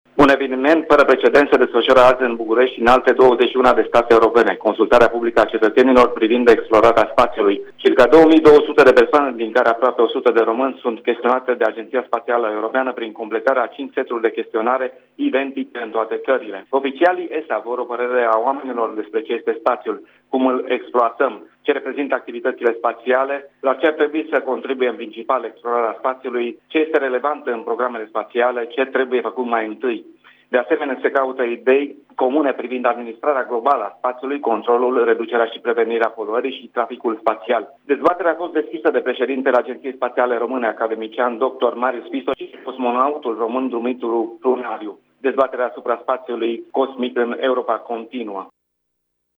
Viitorul domeniului spațial a fost abordat de ESA printr-o consultare publică, ce se desfășoară astăzi la București.